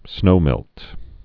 (snōmĕlt)